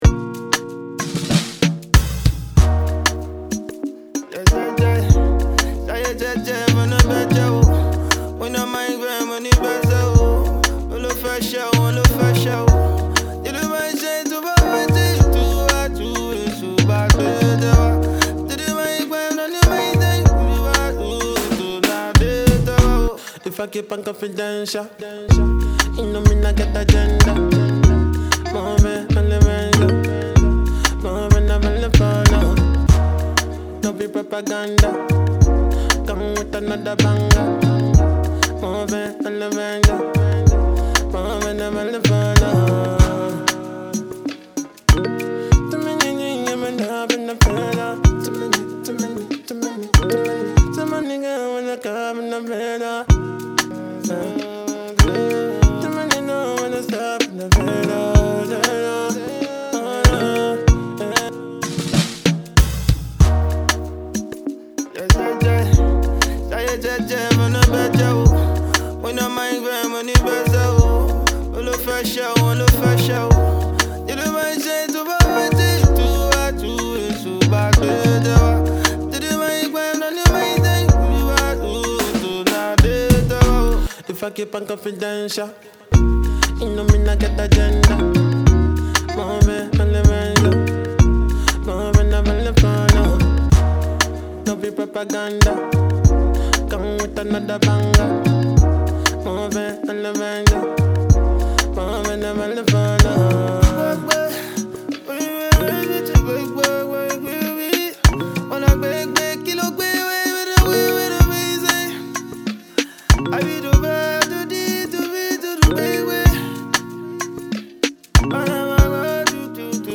Nigeria Music
Nigerian wordsmith singer